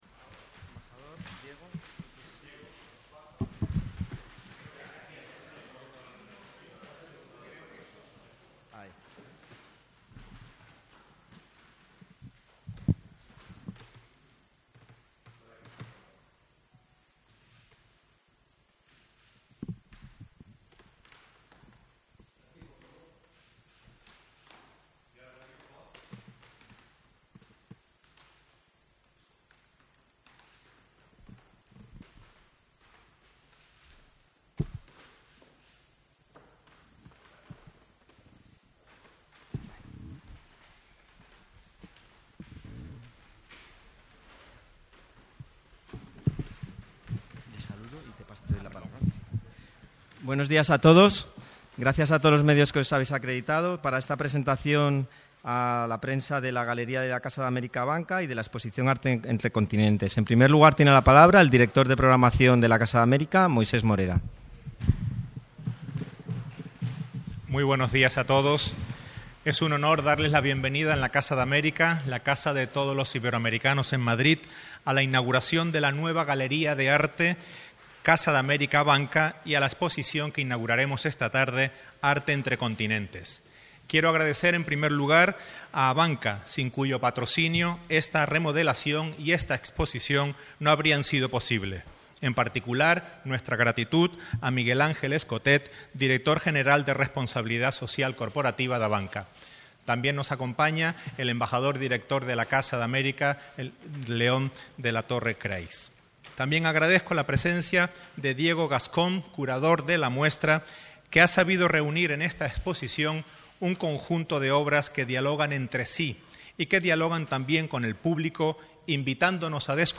Material descargable: 📷 Fotos de la exposición 📷 Fotos de la rueda de prensa (18/09/25, 11:00) 📷 Fotos de la inauguración (18/09/25, 19:30) 🎥 Recursos de vídeo 🔊 Audio de la rueda de prensa 🖼 Listado de obras y artistas